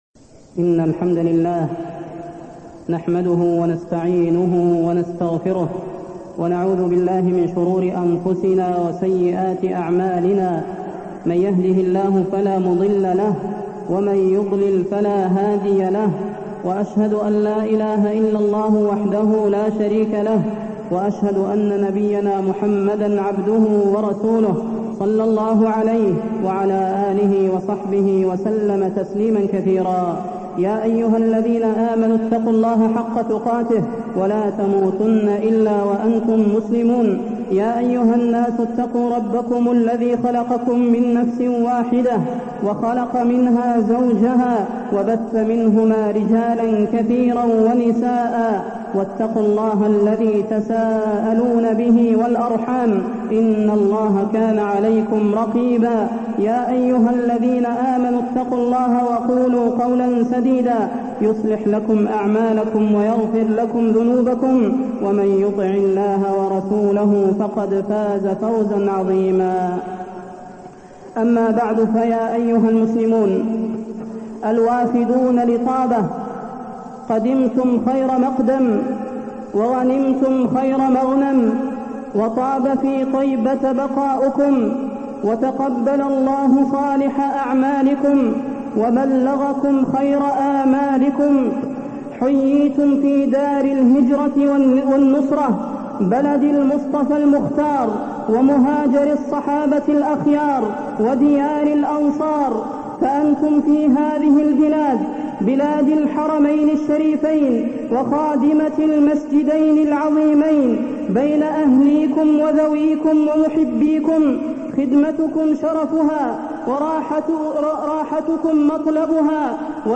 تاريخ النشر ٢٥ ذو القعدة ١٤٢٢ هـ المكان: المسجد النبوي الشيخ: فضيلة الشيخ د. صلاح بن محمد البدير فضيلة الشيخ د. صلاح بن محمد البدير آداب زيارة المسجد النبوي